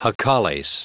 Prononciation du mot jacales en anglais (fichier audio)
Prononciation du mot : jacales